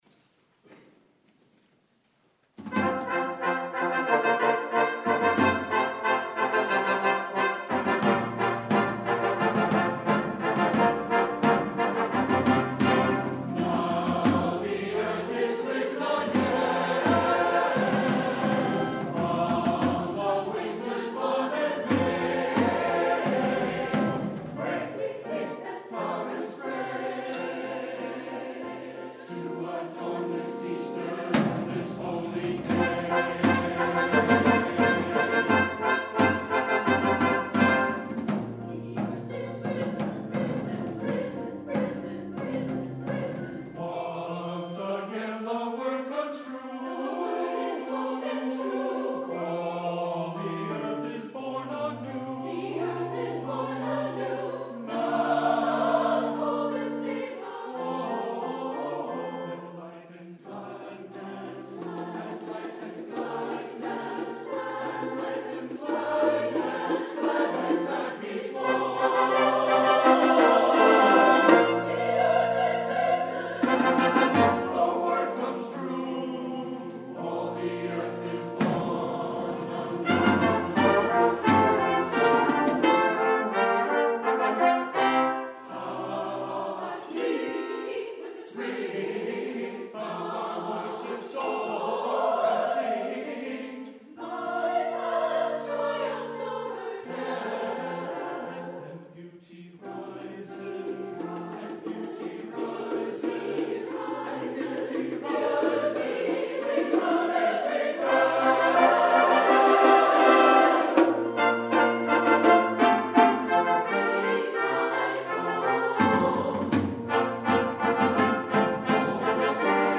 for SATB Chorus, Organ, Brass Quartet, and Percussion (2012)
The livelier music then returns and rises to the end.